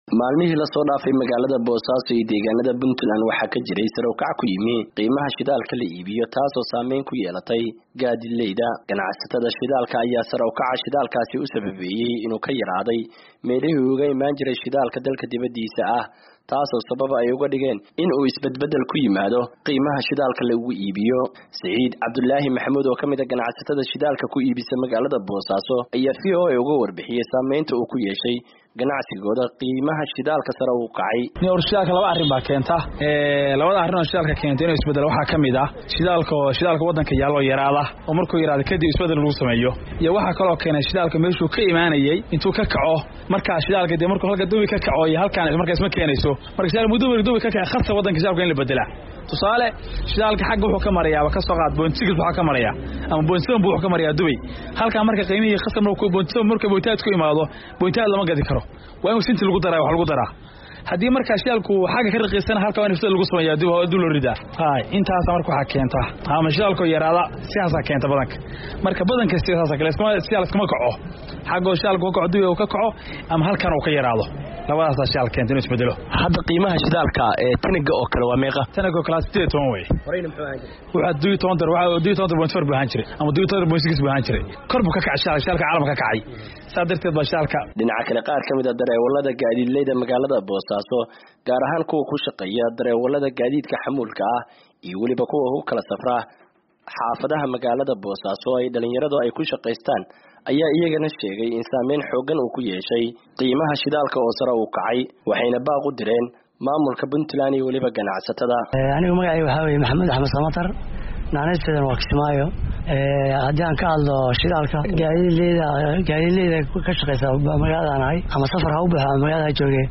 Qaar ka mida dadweynaha ku nool magaalada Boosaaso ayaa cabasho ka muujiyey, sare u kac ku yimi qiimaha shidaalka, kaas oo saamayn ku yeeshay maciishaddii iyo noloshii caadiga ahayd. Warbixintan waxaa Bosaso kasoo diray